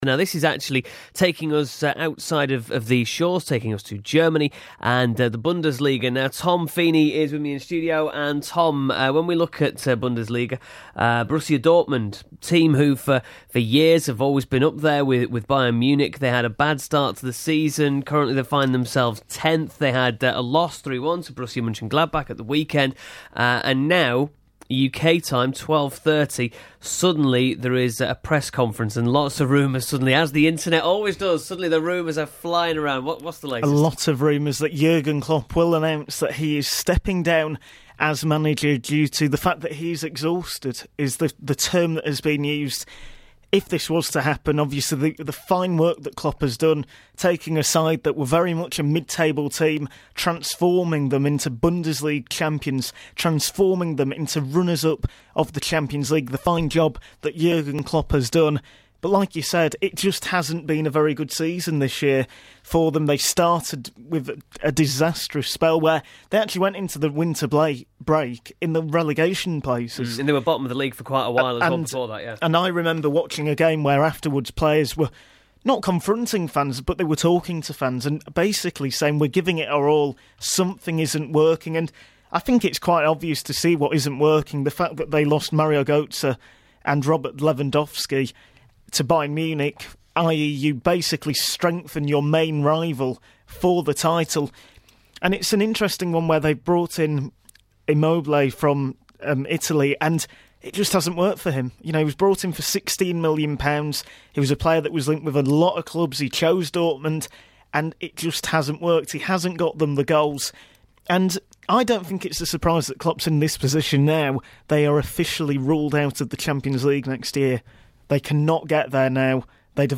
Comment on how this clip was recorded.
This is a clip from Radio Yorkshire's Daytimes Show